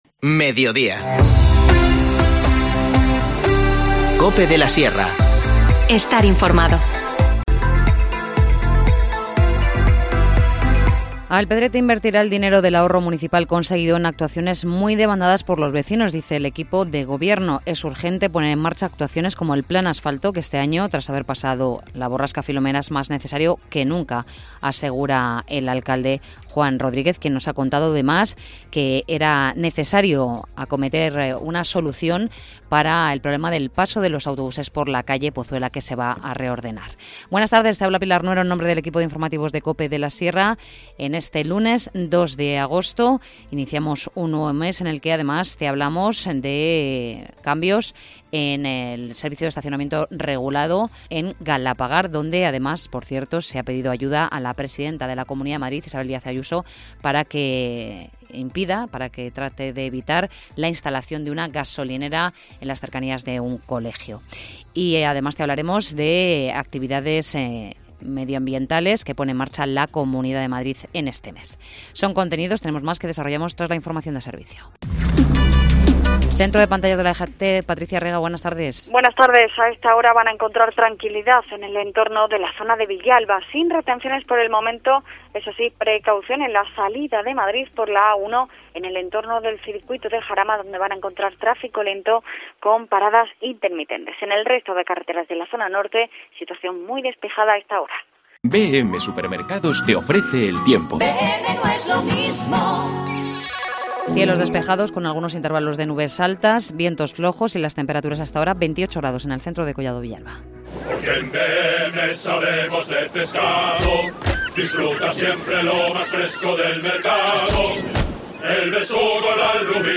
Informativo Mediodía 2 agosto